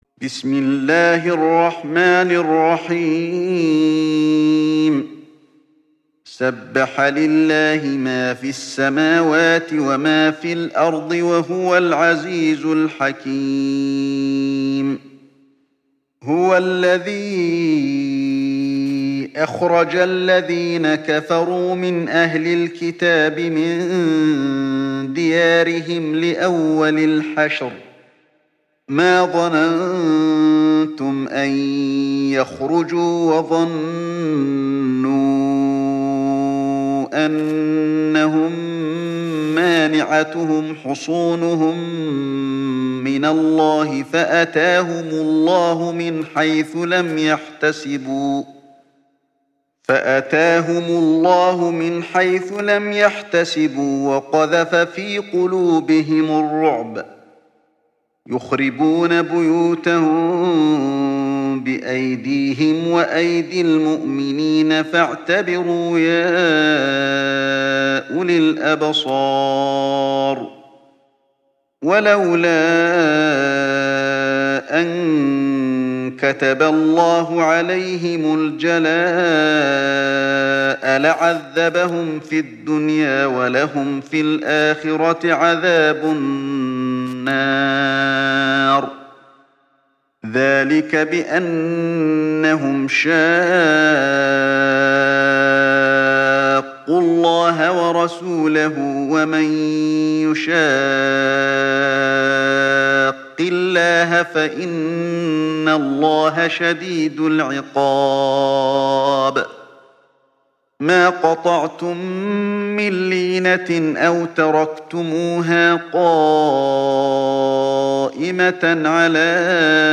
تحميل سورة الحشر mp3 بصوت علي الحذيفي برواية حفص عن عاصم, تحميل استماع القرآن الكريم على الجوال mp3 كاملا بروابط مباشرة وسريعة